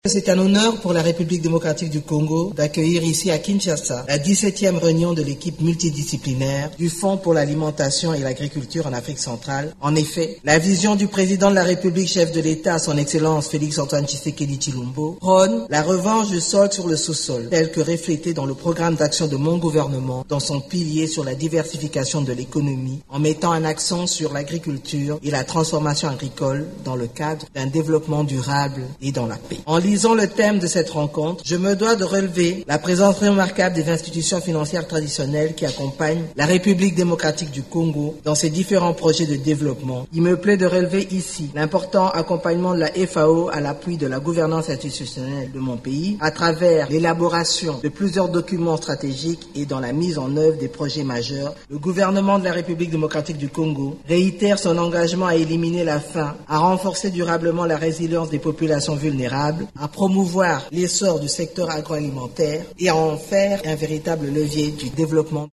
Elle l’a déclaré lors du lancement de la XVIIe réunion de l’équipe multidisciplinaire du Fonds pour l’alimentation et l’agriculture (FAO) en Afrique centrale, rassemblant neuf pays membres de la sous-région.
Vous pouvez écouter l’extrait du discours d’ouverture de la Première ministre de la RDC Judith Suminwa :